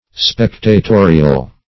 Spectatorial \Spec`ta*to"ri*al\, a.
spectatorial.mp3